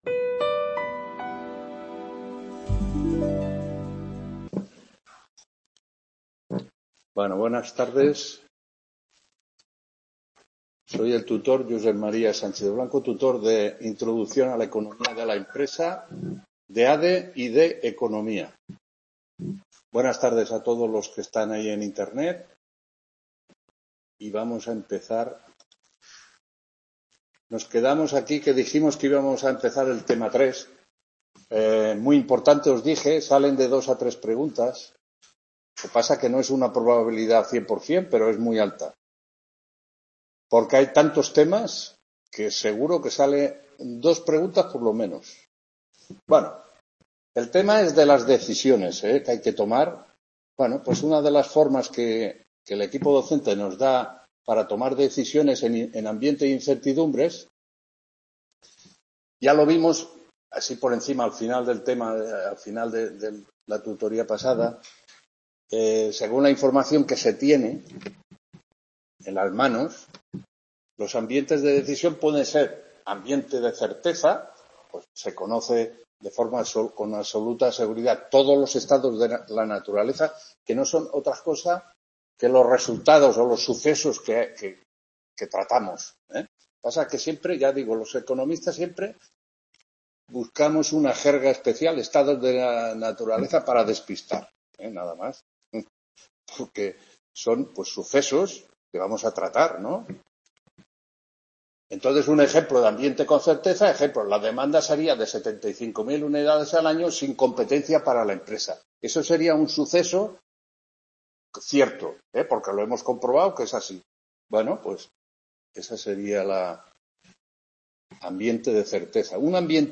5ª TUTORÍA INTRODUCCIÓN A LA ECONOMÍA DE LA EMPRESA 14… | Repositorio Digital